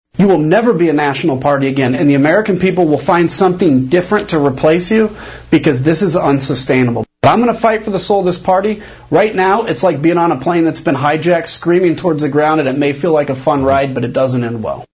Kinzinger spoke on NBC’s Meet the Press.